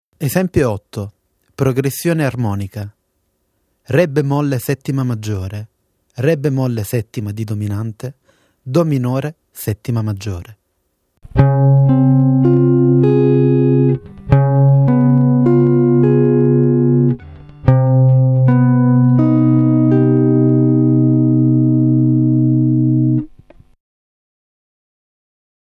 a) | DbMaj7     Db7     |     Cmin (Maj7)     |